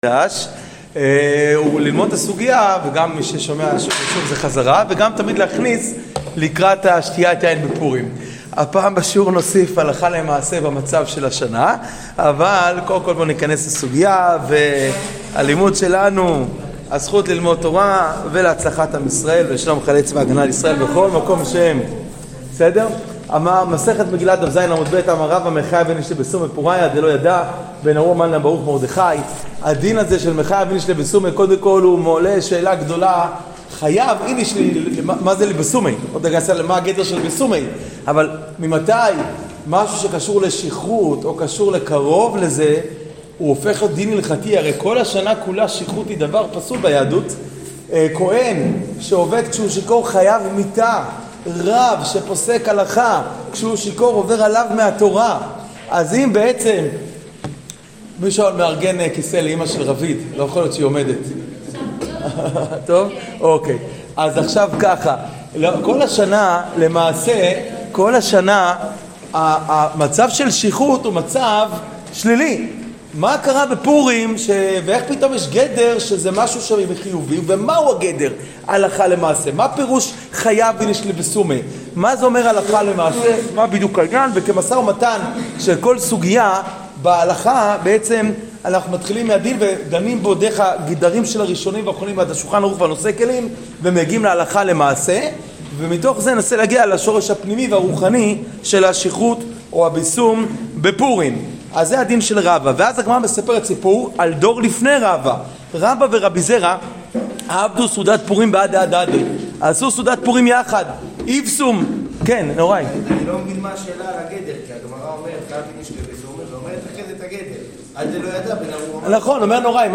שיעור כללי - חייב איניש לבסומי בפורייא